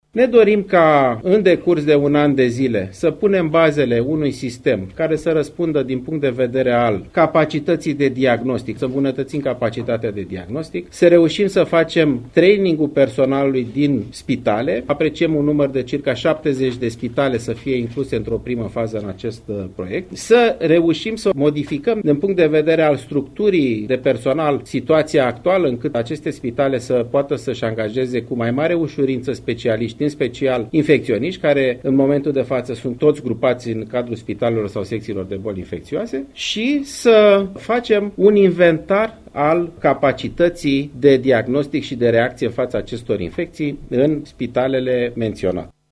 În următoarele 2 luni, în 70 de spitale de urgență, institute, spitale mari vor începe mai multe evaluări pe baza unor chestionare care vor fi analizate de către specialiștii Institutului Național de Sănătate Publică, a declarat consilierul onorific al ministrului sănătății, profesor doctor Alexandru Rafila: